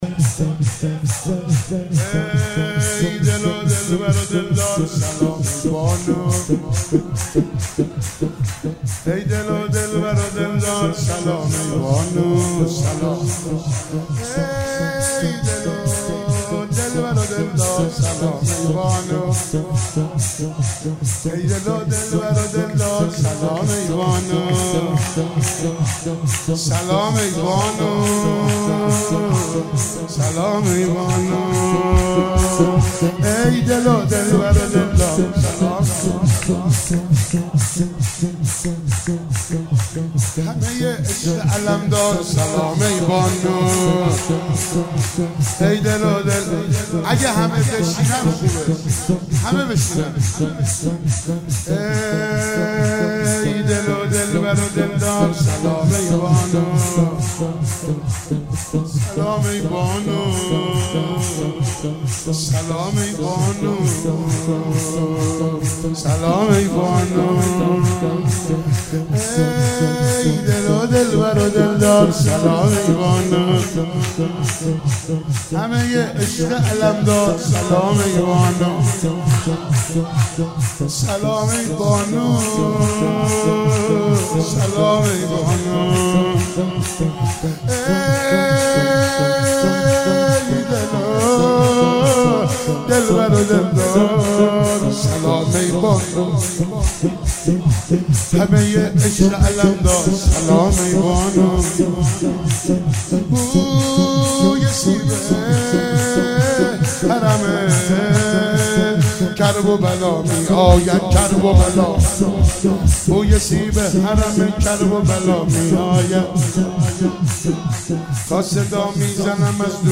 ولادت حضرت رقیه سلام الله علیها97 - سرود - سلام ای بانو